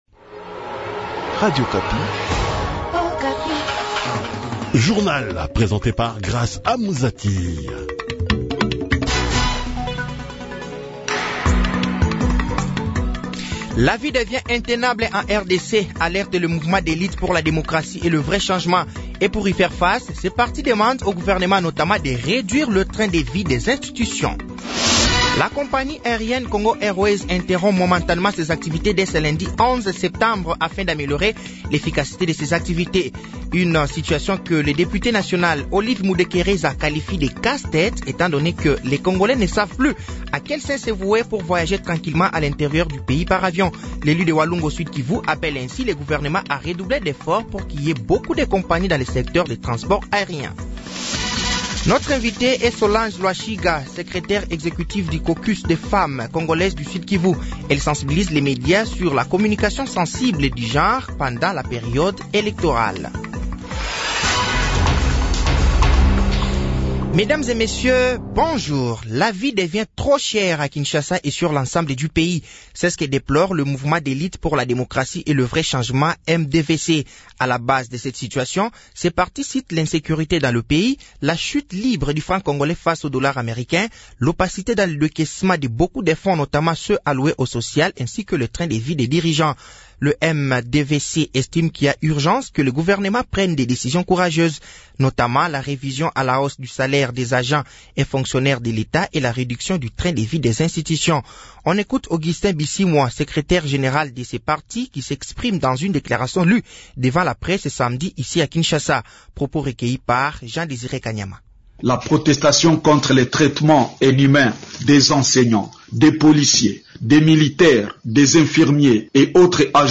Journal matin
Journal français de 6h de ce lundi 11 septembre 2023